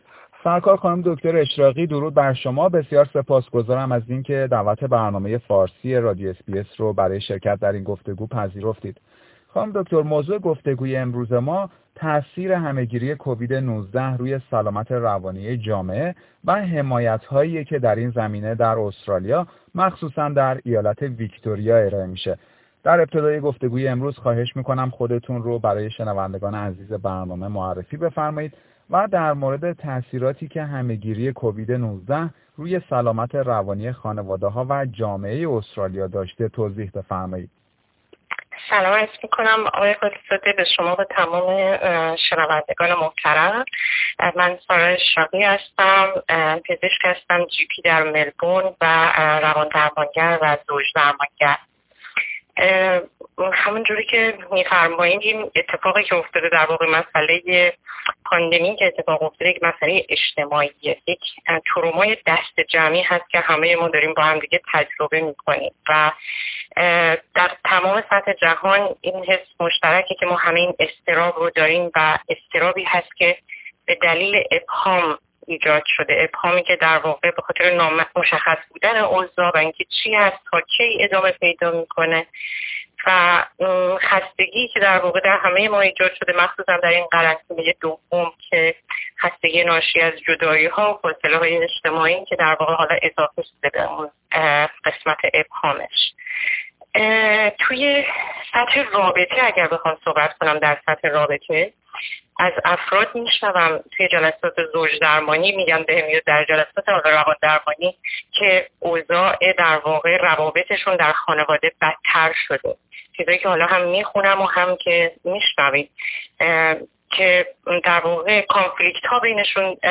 گفتگو با یک پزشک و روان درمانگر در مورد نحوه دسترسی به حمایت های رایگان در زمینه سلامت روانی در استرالیا